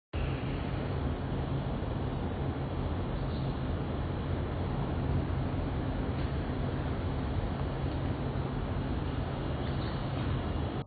均能音量: 54.2 dBA 最大音量: 66.6 dBA
位於中研院植微所外的樹林間，能聽到鳥叫、蟬鳴、冷氣運轉聲，整體算安靜 聲音類型: 動物、野生動物、鳥